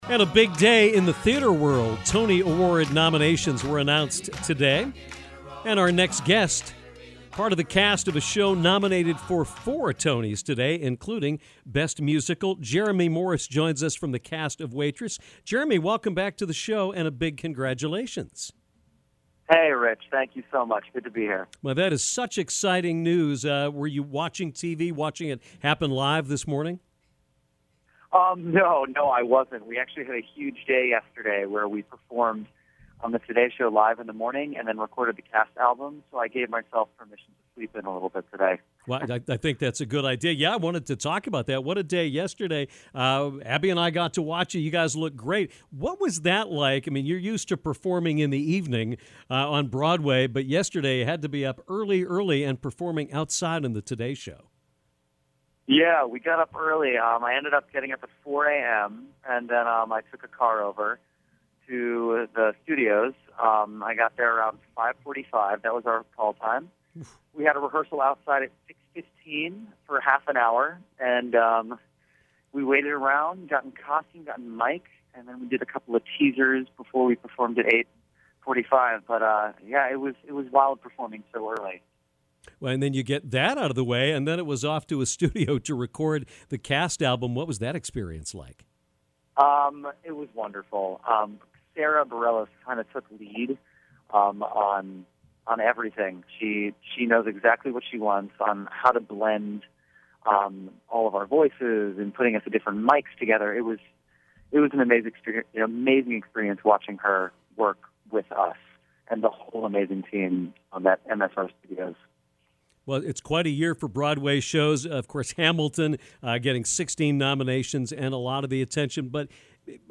called into the show